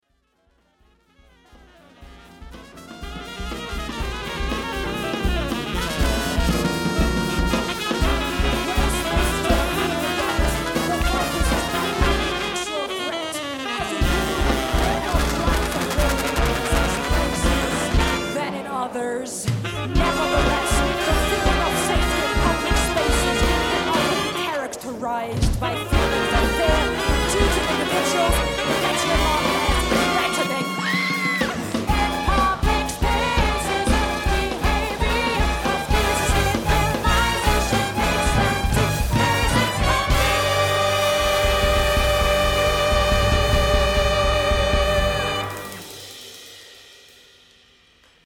eine Suite in 3 Sätzen